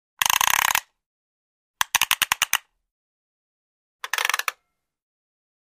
Детская заводная игрушка с механическим заводом